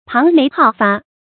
龐眉皓發 注音： ㄆㄤˊ ㄇㄟˊ ㄏㄠˋ ㄈㄚˋ 讀音讀法： 意思解釋： 龐：雜色；皓：潔白。